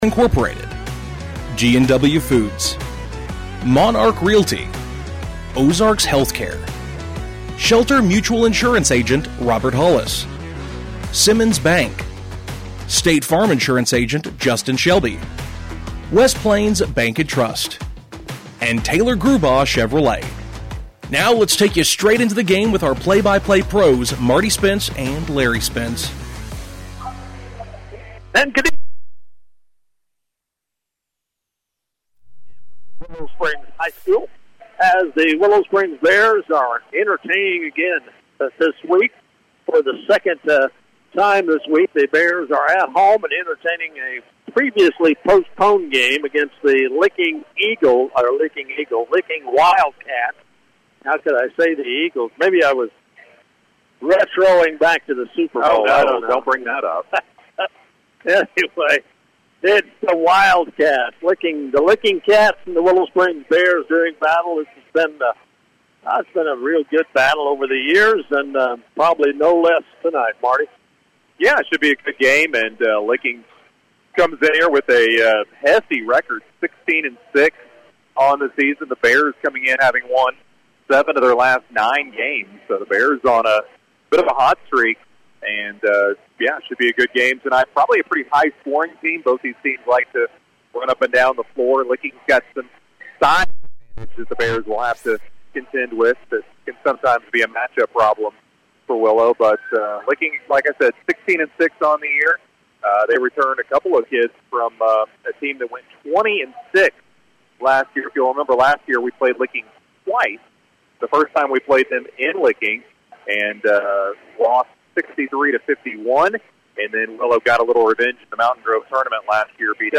Game Audio Below: Quarter 1: The Bears get a lead over the Wildcats with the final score of the first quarter being 12-8 Willow.